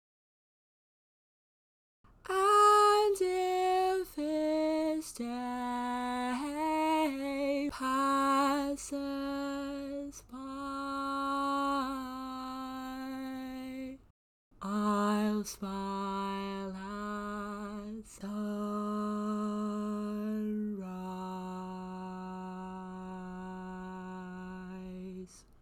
Key written in: B Major
Each recording below is single part only.